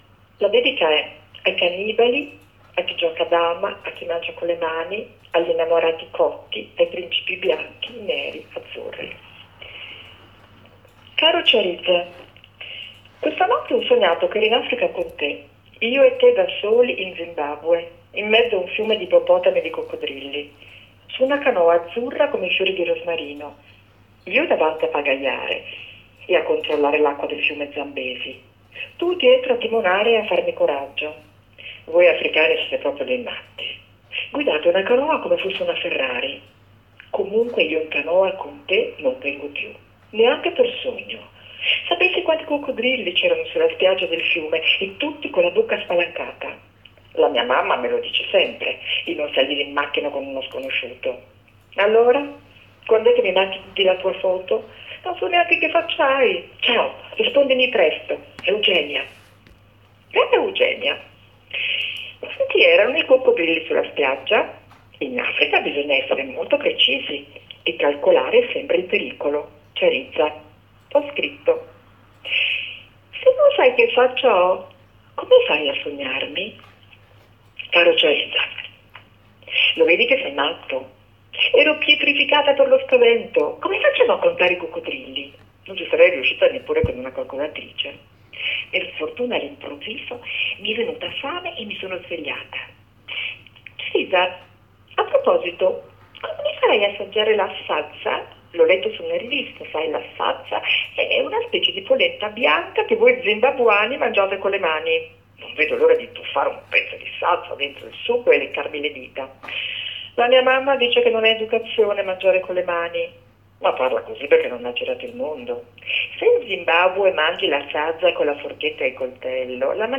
Estratto di "Coccodrilli a colazione"